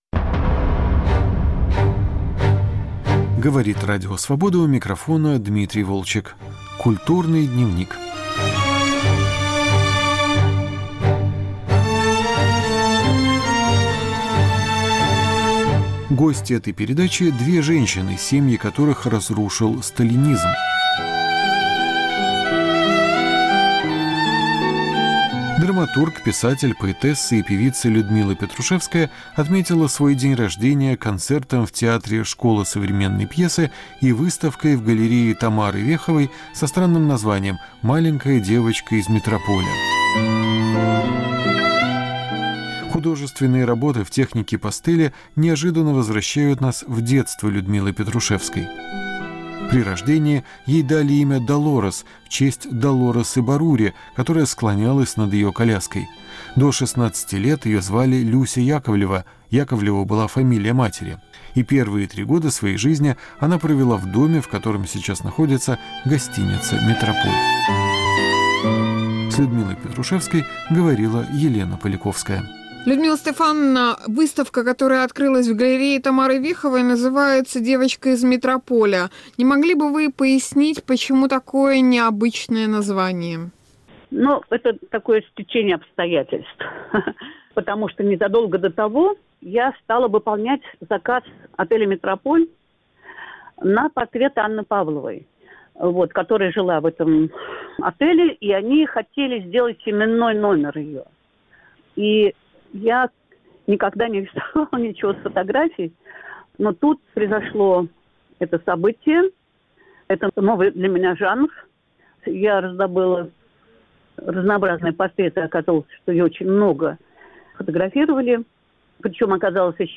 Сталинизм и настоящее. Разговор с Людмилой Петрушевской и Мартой Месарош